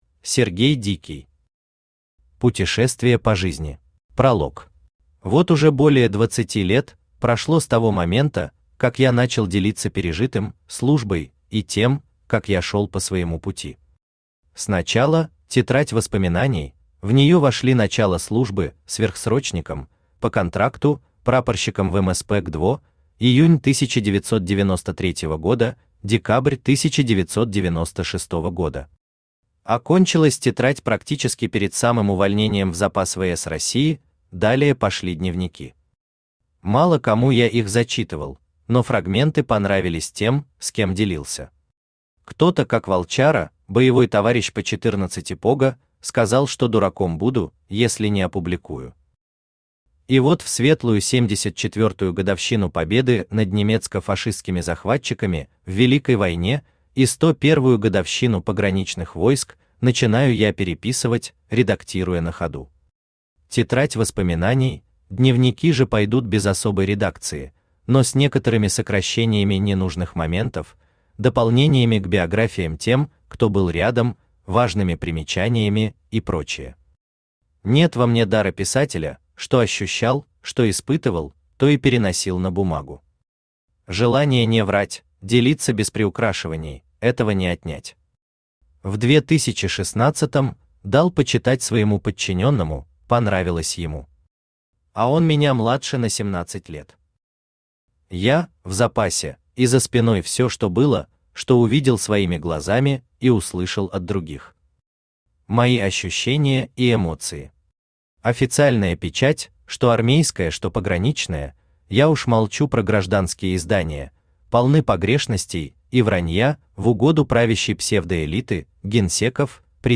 Aудиокнига Путешествие по жизни Автор Сергей Дикий Читает аудиокнигу Авточтец ЛитРес.